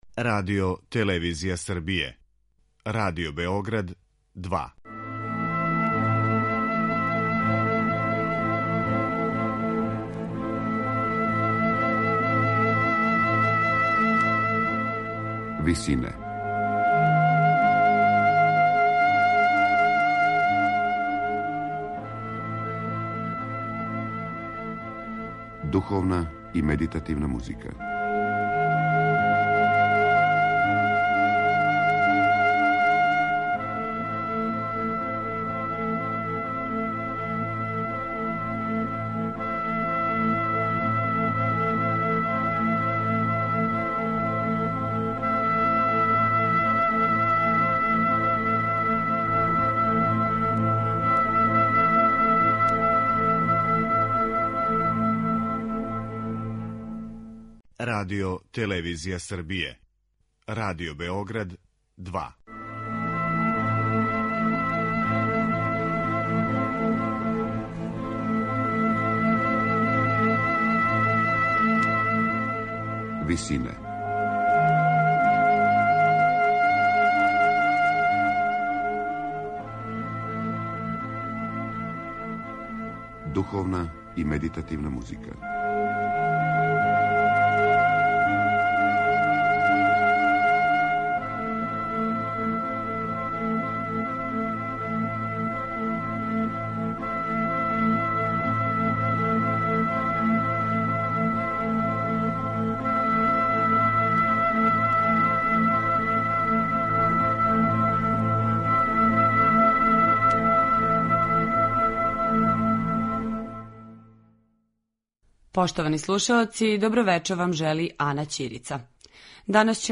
ансамбл за рану музику